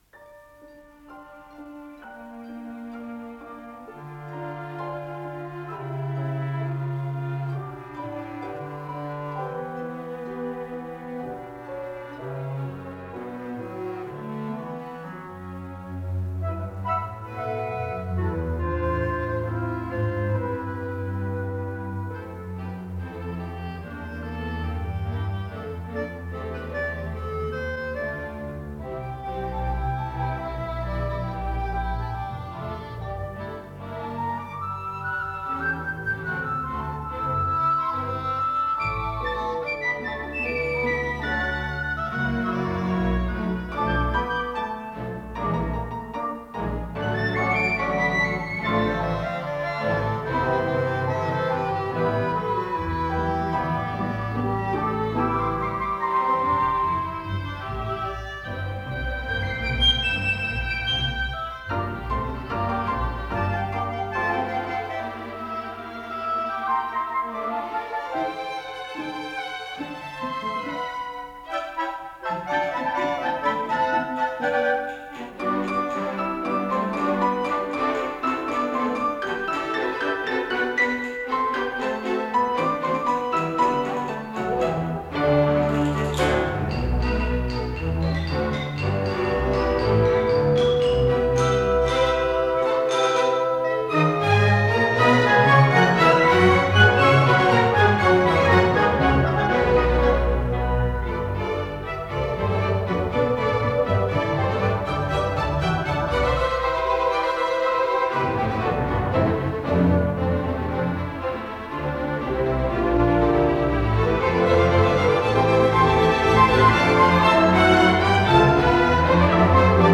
с профессиональной магнитной ленты
Андантино, темпо рубато
ВариантДубль моно